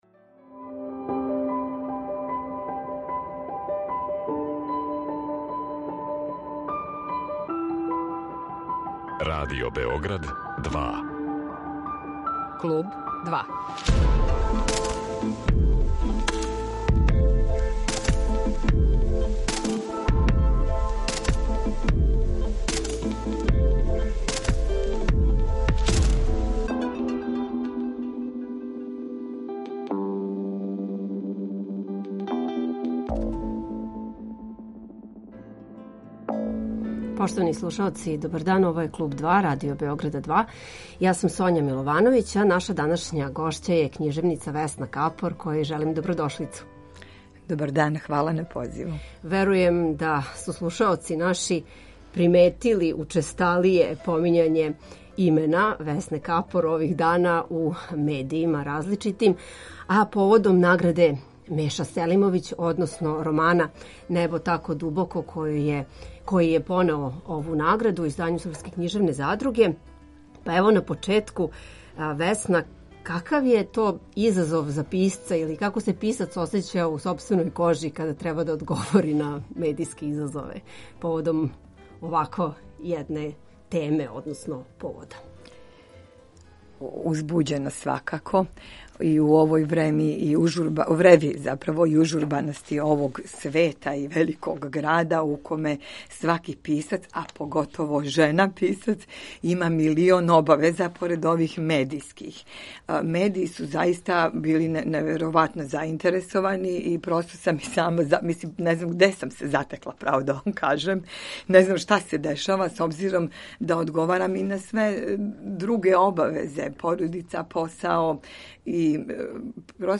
Како види свој досадашњи стваралачки пут, колико јој је суд обичних читалаца а колико критике важан, прави ли међу њима разлику и какву, како књижевна стварност гледа на поменути „изгон" емоција, још неке су теме нашег данашњег разговора.